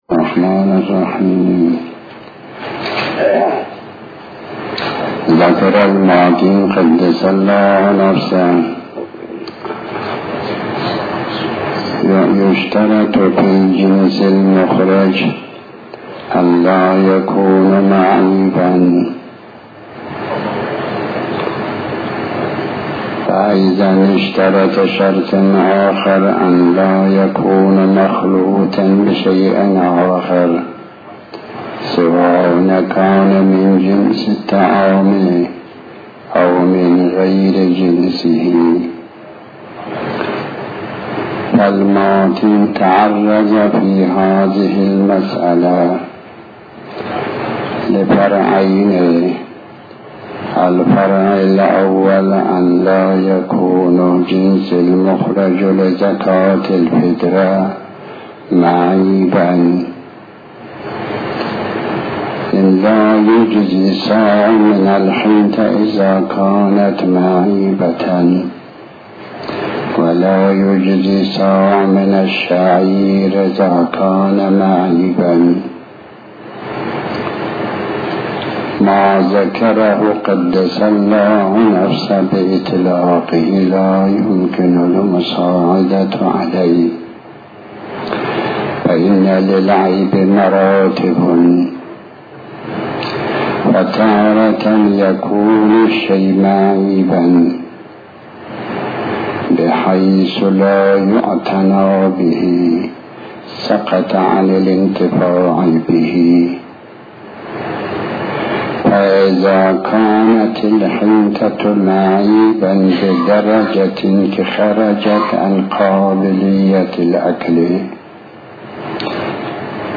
تحمیل آیةالله الشيخ محمداسحاق الفیاض بحث الفقه 38/08/09 بسم الله الرحمن الرحيم الموضوع:- زكاة الفطرة.